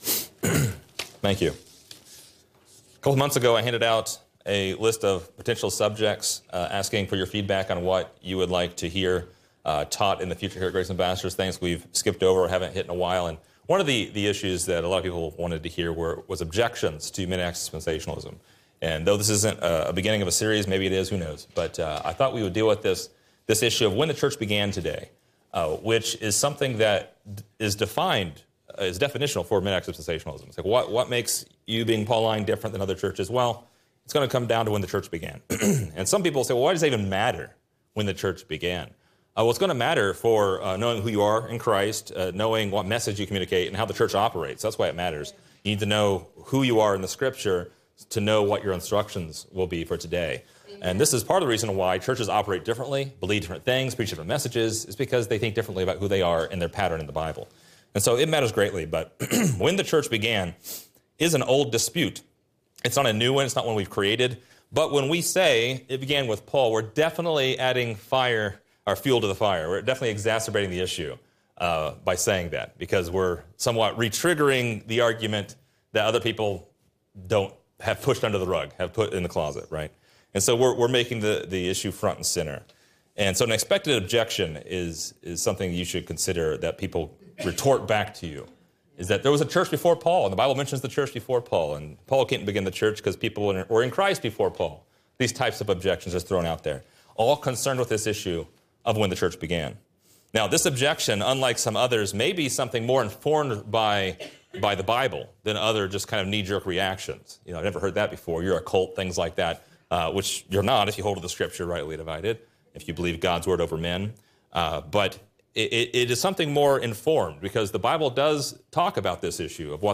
This lesson compares and contrasts three different Bible churches to see which is the Body of Christ today.